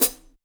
Original creative-commons licensed sounds for DJ's and music producers, recorded with high quality studio microphones.
Clean Hat Sample F# Key 38.wav
closed-high-hat-sound-f-sharp-key-47-HKg.wav